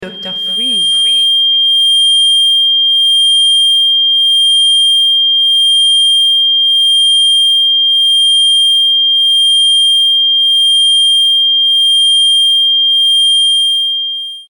La fréquence 3200 Hz est utilisée dans les thérapies vibratoires pour favoriser le renouvellement cellulaire.